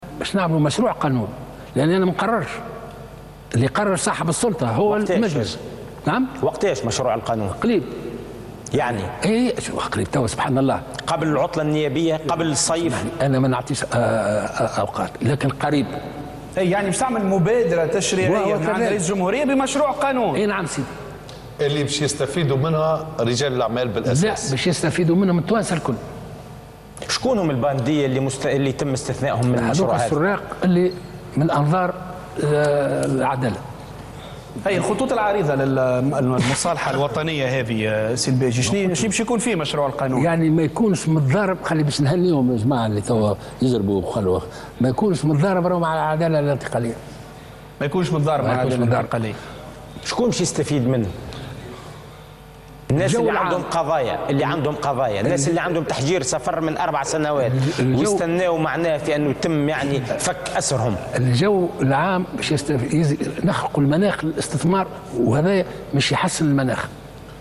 قال رئيس الجمهورية الباجي قائد السبسي في حوار مع قناة "الحوار التونسي" إن رئاسة الجمهورية ستتقدّم بمبادرة تشريعية حول المصالحة الوطنية.